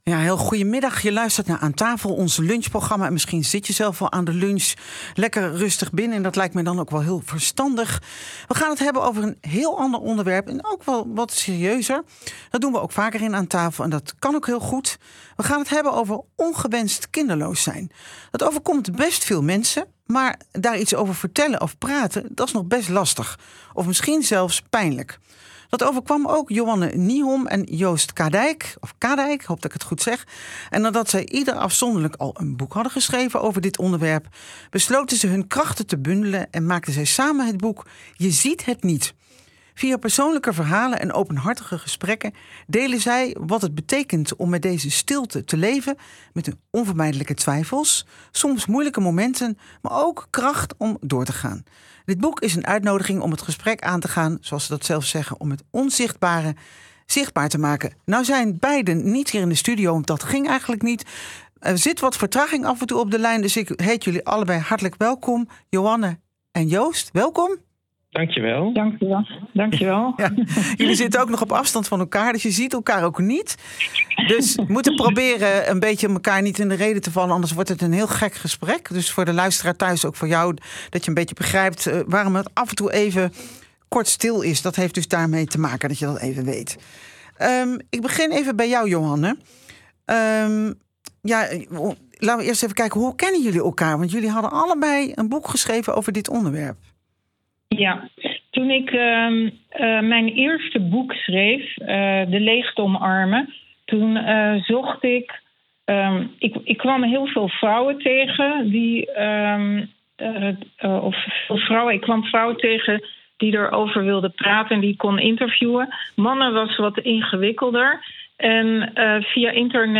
interview bij RTV Utrecht